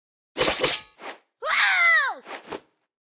Media:Sfx_Anim_Ultra_Nita.wav 动作音效 anim 在广场点击初级、经典、高手和顶尖形态或者查看其技能时触发动作的音效
Sfx_Anim_Super_Nita.wav